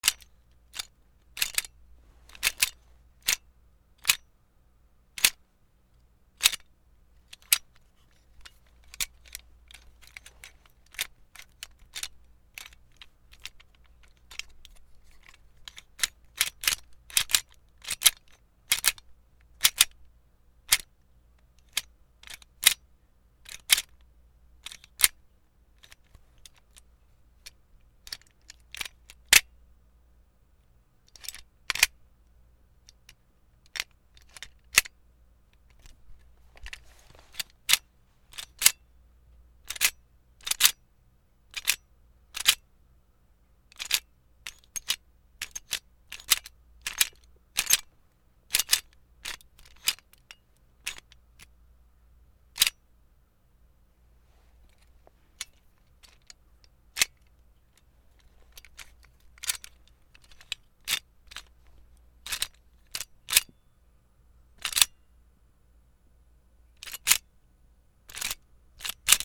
/ H｜バトル・武器・破壊 / H-05 ｜銃火器
銃 カチャカチャ イメージ 機械音